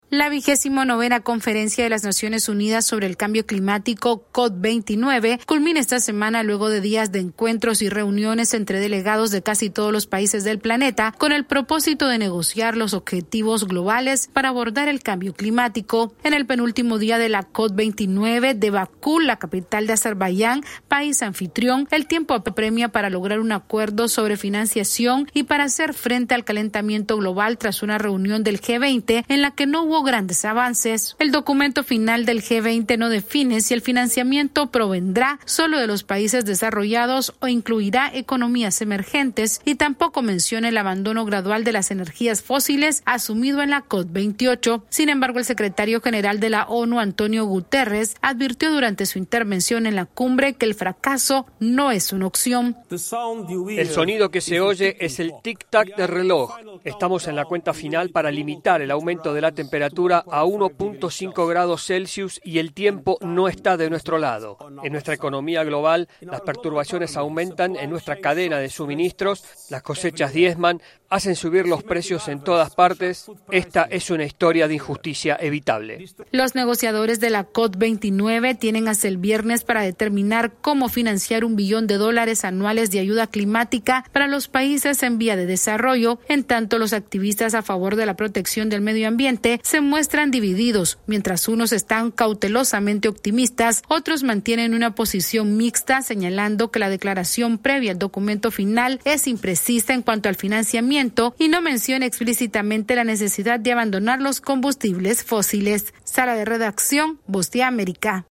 La COP29 de Bakú se aproxima a su fin con la urgencia de lograr un acuerdo sobre el financiamiento contra el calentamiento global ante la mirada atenta de ambientalistas que exigen una pronta solución a este problema. Esta es una actualización de nuestra Sala de Redacción.